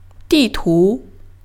di4-tu2.mp3